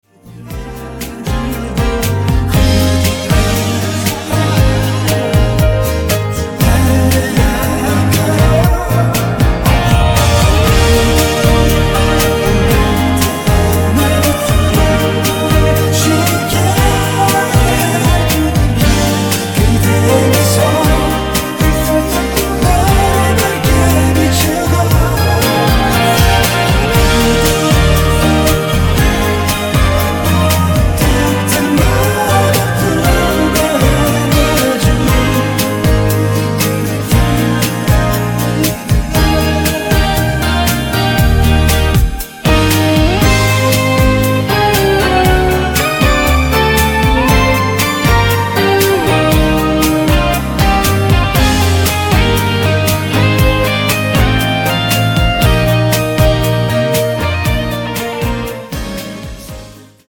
음정 코러스
장르 축가 구분 Pro MR